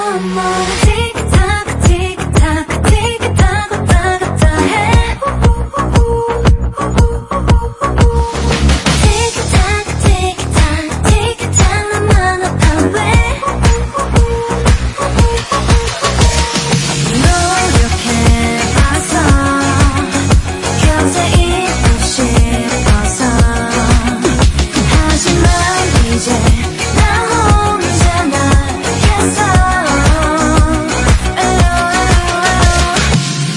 Girl Group
South Korean